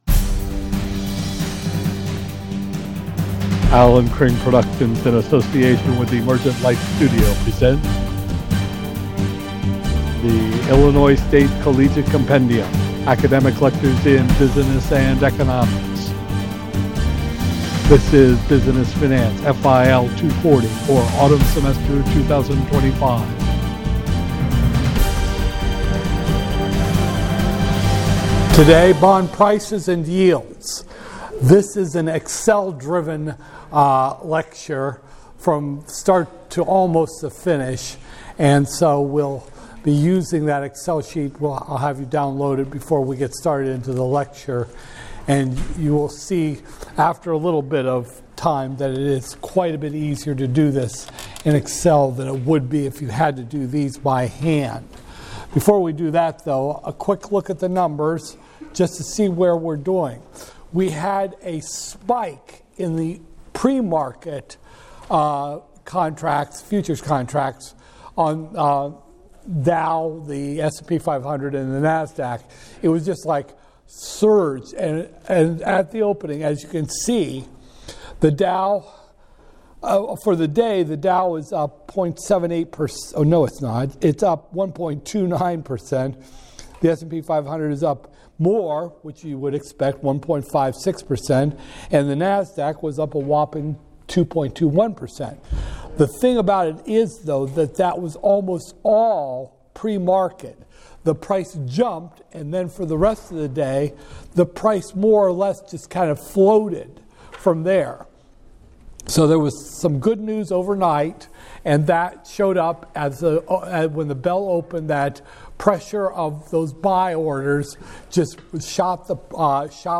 Business Finance, FIL 240-002, Spring 2025, Lecture 15